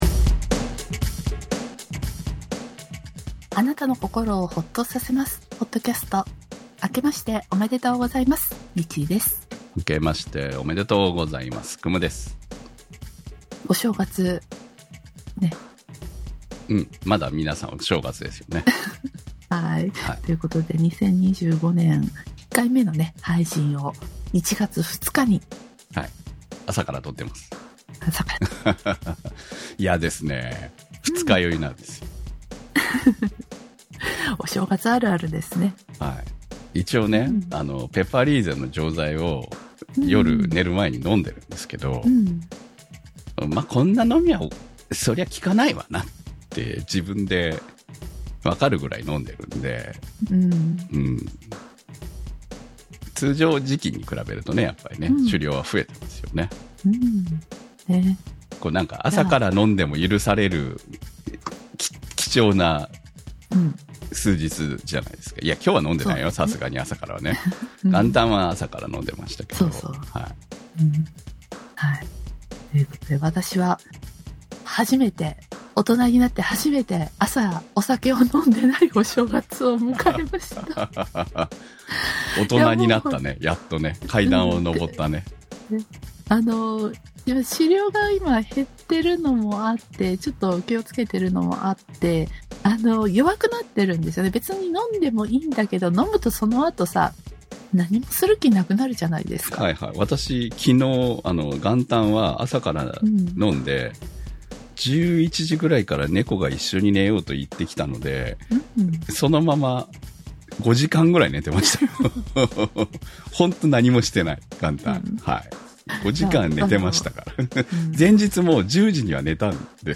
二人のお正月の過ごし方や食べた物の話などを、例年より年始感増し増しでお送りします。いつも通りほぼ寝正月な我々のフリートークをどうぞ。